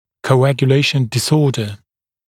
[kəuˌægjə’leɪʃn dɪ’sɔːdə][коуˌэгйэ’лэйшн ди’со:дэ]нарушение свертываемости крови